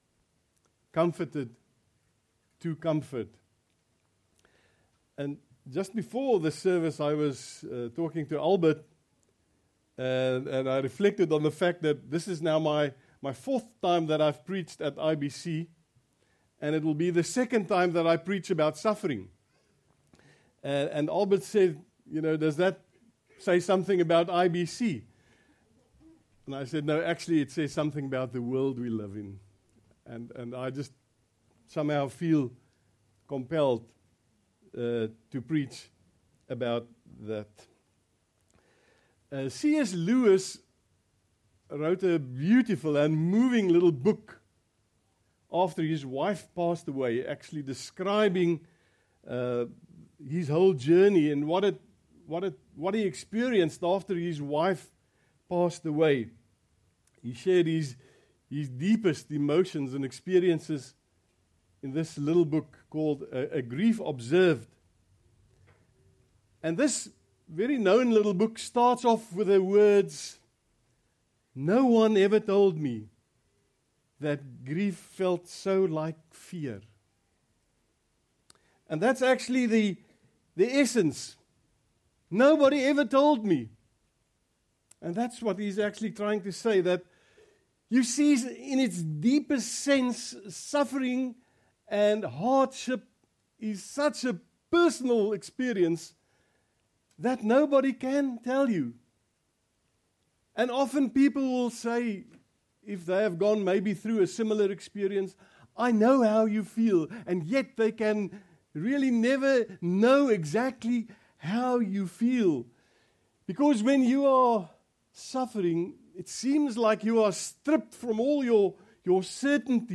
When preaching about suffering and hardship ….